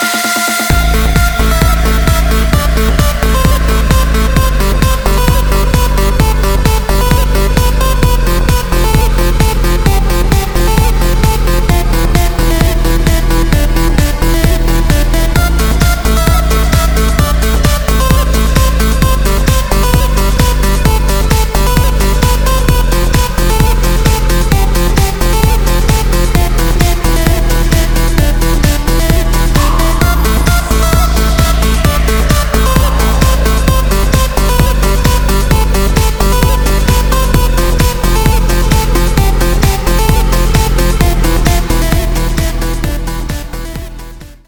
• Качество: 320, Stereo
громкие
мощные
Electronic
без слов
Trance
Стиль: trance